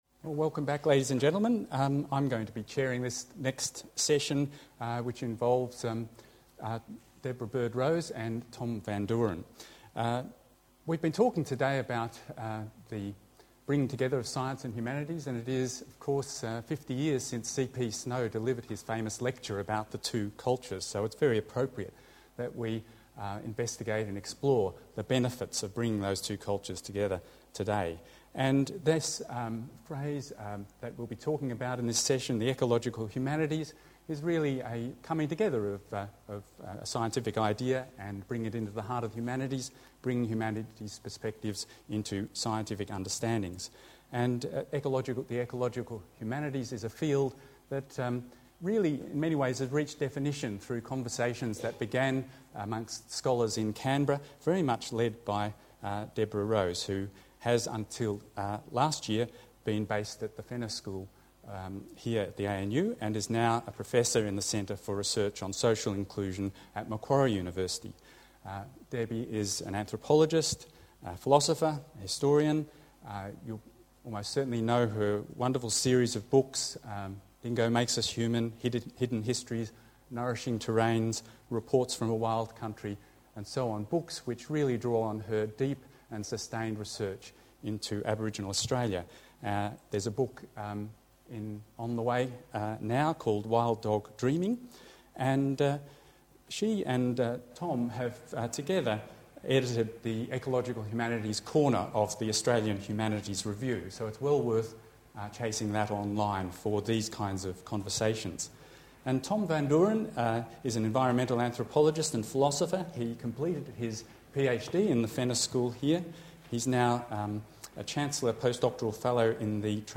Violent Ends, an event hosted by the National Museum of Australia on 11 June 2009, explored these anxieties and the prospects for hope by bringing together a unique group of artists, poets, dancers, singers, scientists, film makers, historians, creative writers and cultural theorists.
in discussion